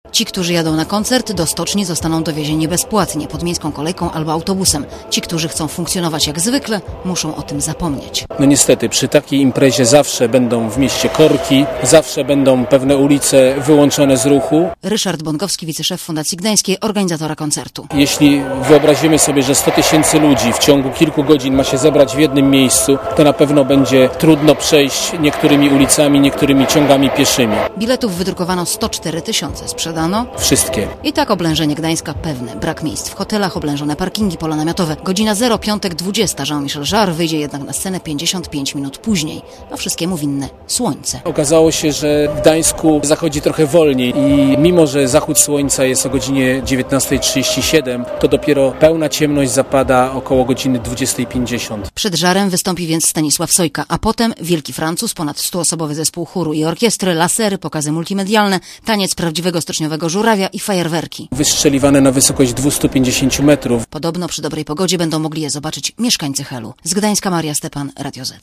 Relacja
reporterki Radia ZET